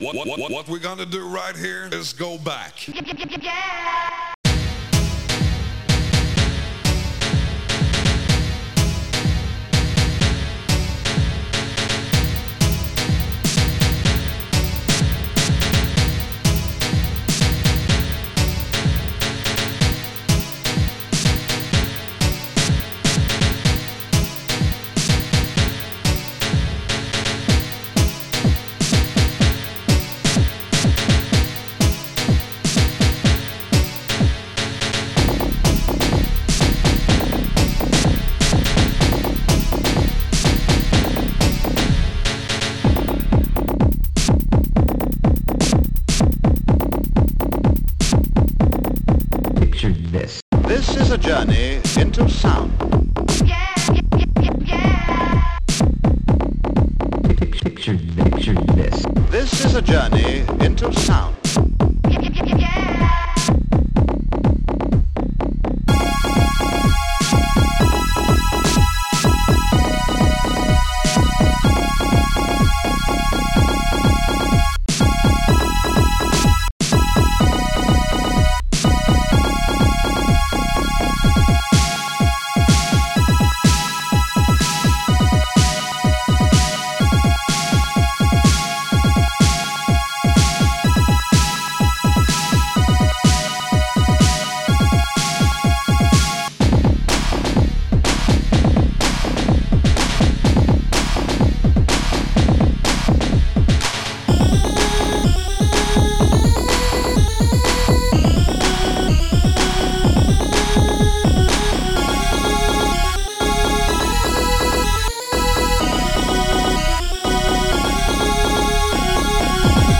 KICK1.SAM
SNARE.SAM
HIHAT.SAM
BASSYNTH.SAM
STRINGS.SAM
CLAP2.SAM